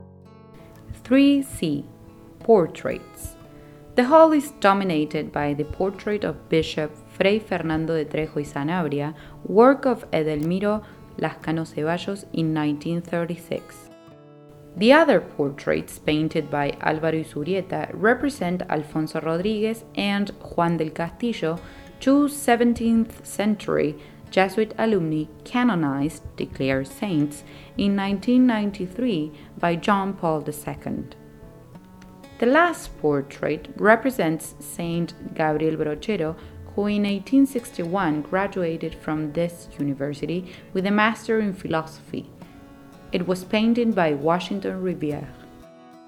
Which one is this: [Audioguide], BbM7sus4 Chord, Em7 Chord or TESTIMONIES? [Audioguide]